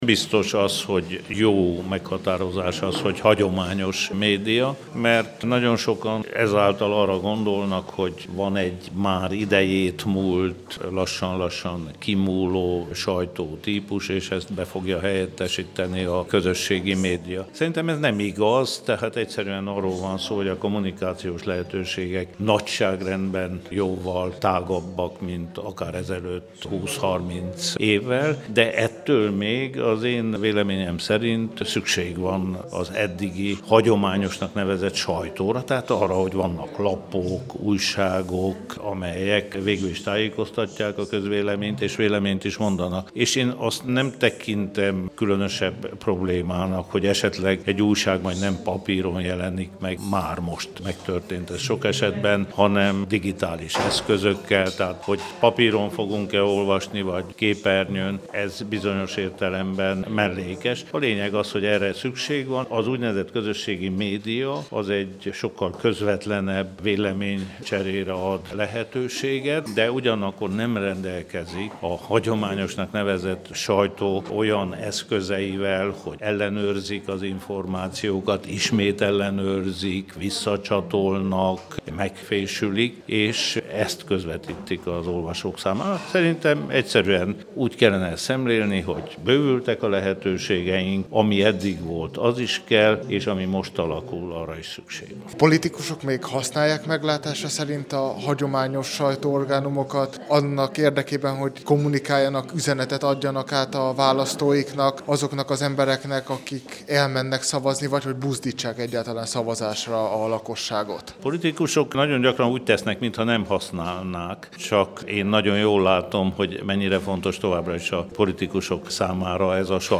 Kerekasztal-beszélgetés a politikum és a sajtó között